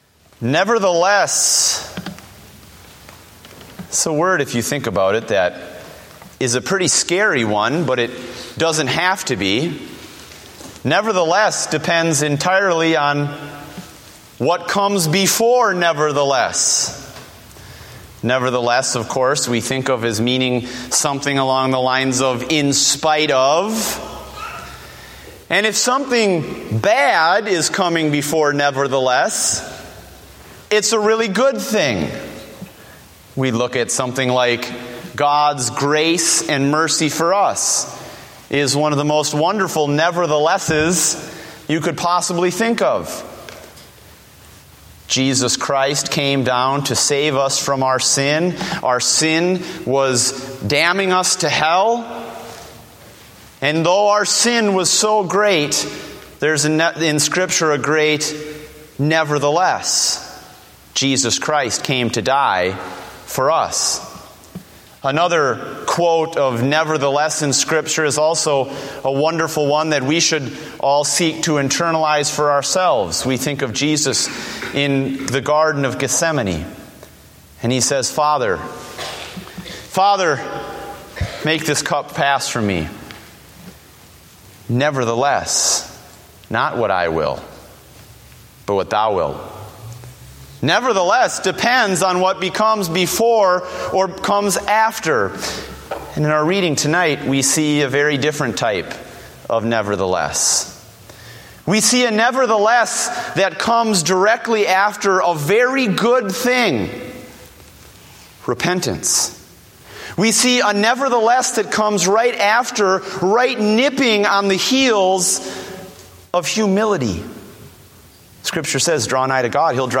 Date: June 8, 2014 (Evening Service)